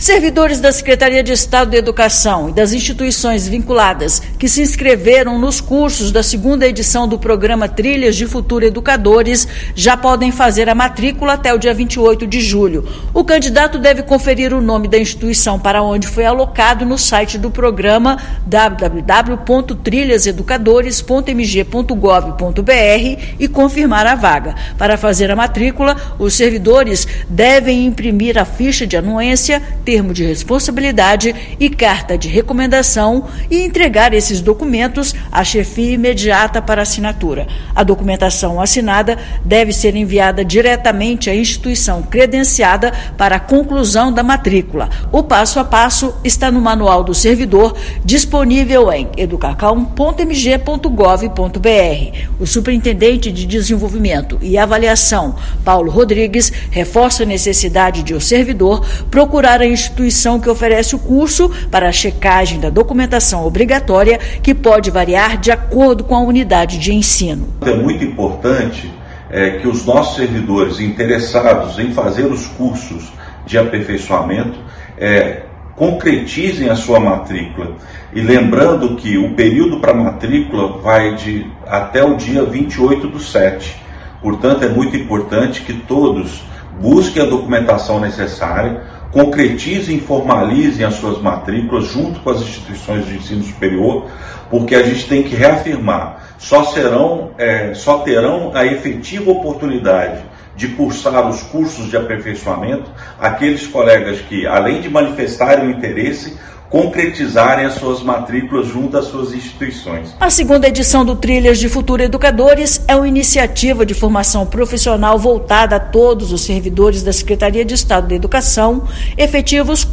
[RÁDIO] Servidores inscritos no Trilhas de Futuro Educadores já podem fazer matrícula nos cursos de aperfeiçoamento
Resultado das alocações pode ser conferido no site do programa, e as inscrições devem ser feitas até 28/7. Ouça matéria de rádio.